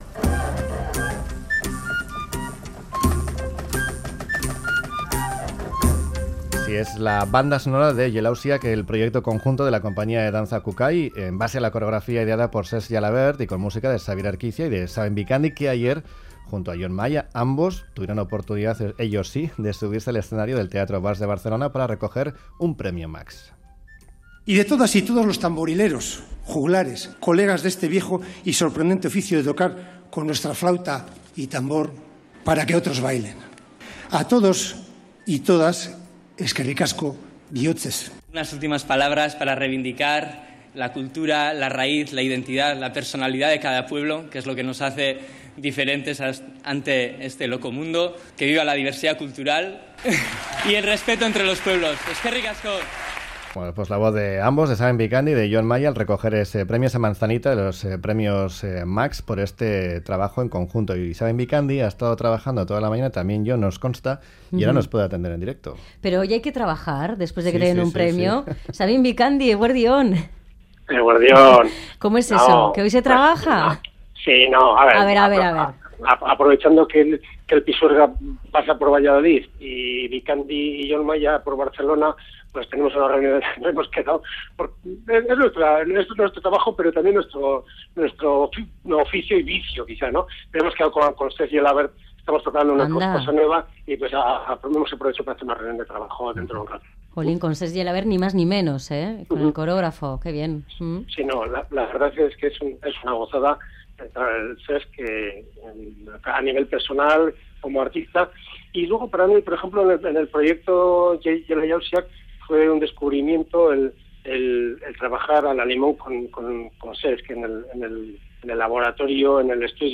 Hablamos con ambos pocas horas después de bajarse del escenario.